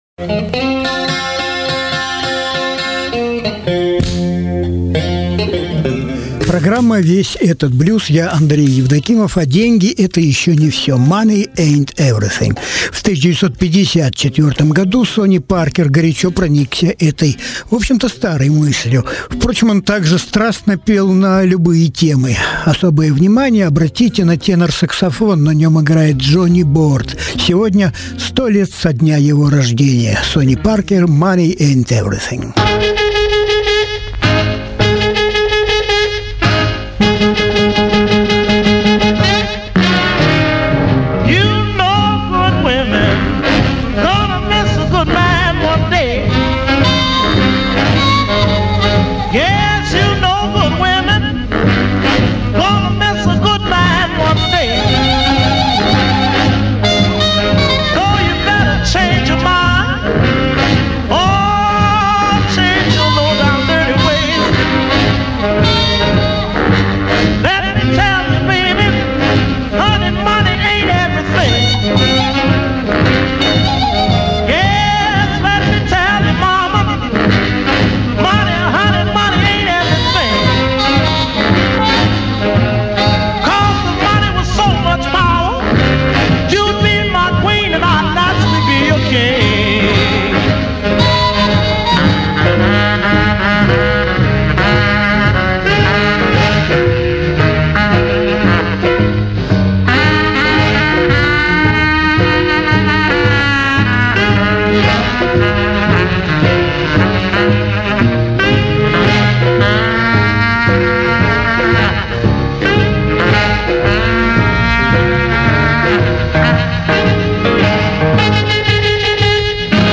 Lil Green - певица. Tracy Nelson - певица и гитаристка